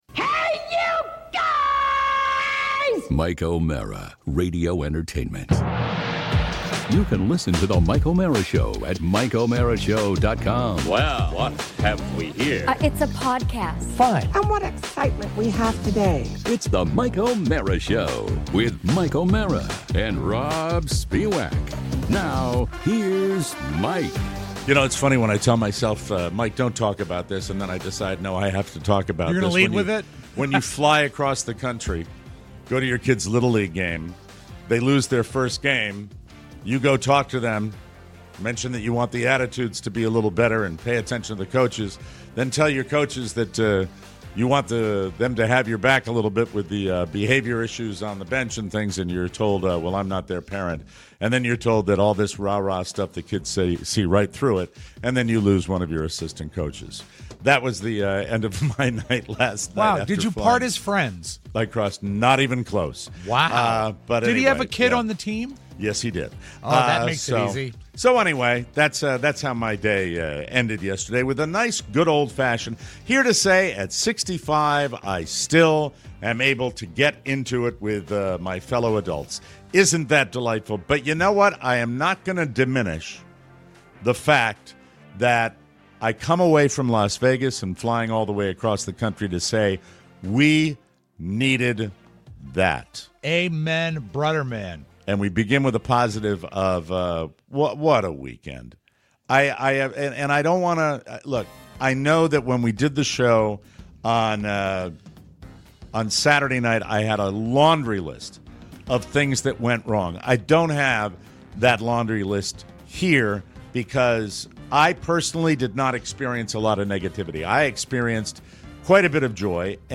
We have all returned for LVNV all in one piece (essentially)... and we are back in studio to recap our Sin City Experience. We realize that we needed this even if we all had a few "issues" with our host hotel.